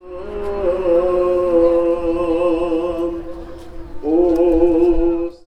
• orthodox priest singing 1.wav
Recorded outdoor near a church with a Tascam DR 40 linear PCM recorder, while a priest is holding Sunday's service of worship.
orthodox_priest_singing_1_r8j.wav